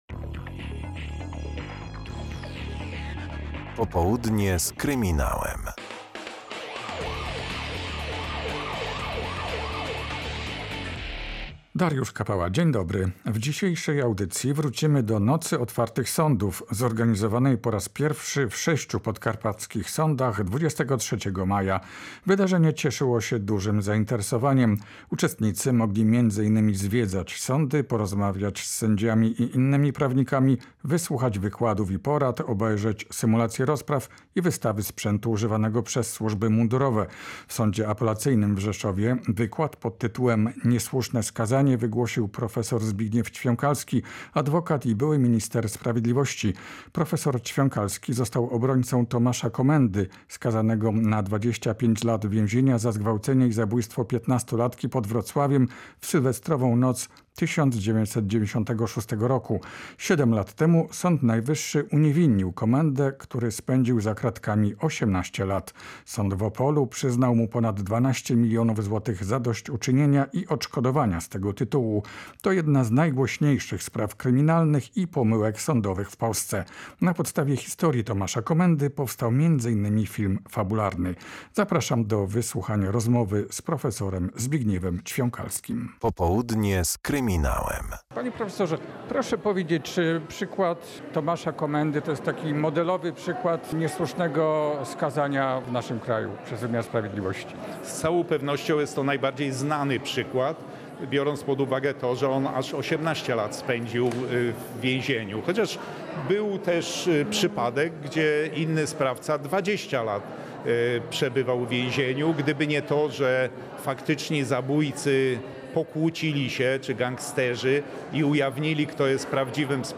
Niesłuszne skazanie – rozmowa z profesorem Zbigniewem Ćwiąkalskim